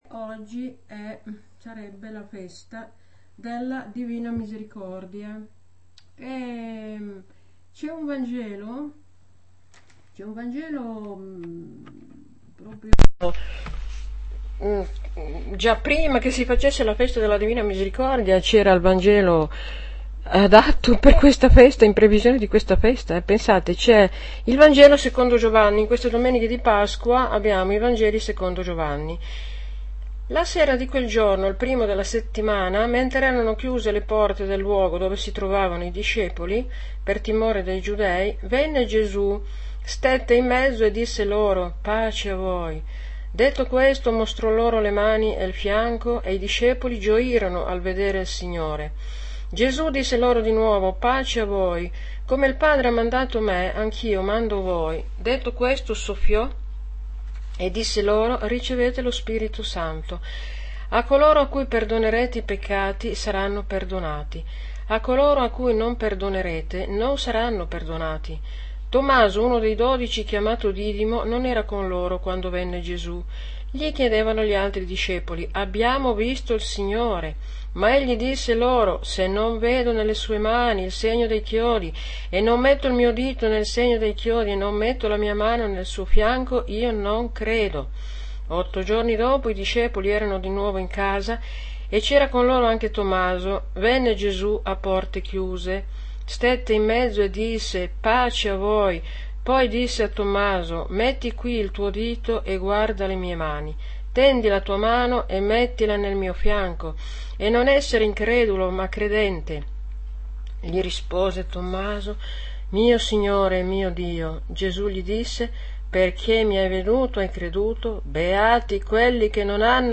AUDIO Audio commento alla liturgia - Gv 20,19-31